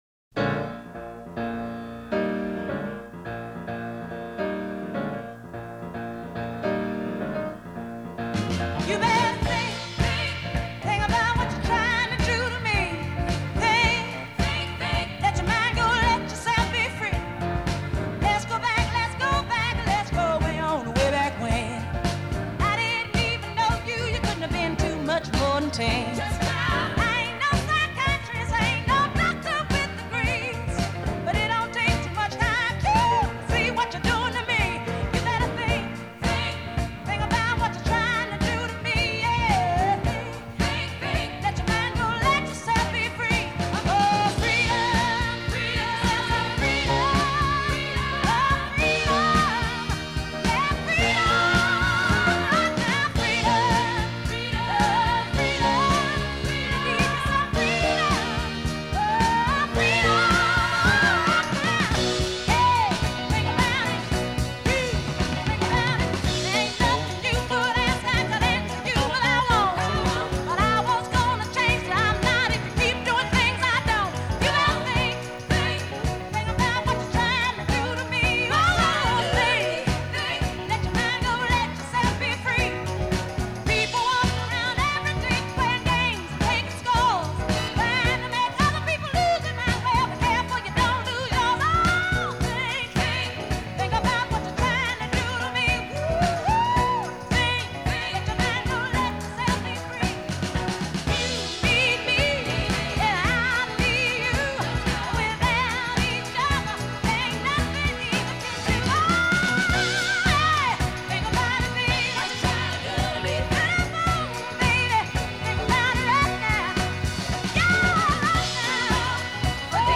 TEMPO : 110
• Intro piano seul, 4 mesures
• 16 mesures de fond Blues sur I et IV